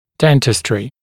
[‘dentɪstrɪ][‘дэнтистри]стоматология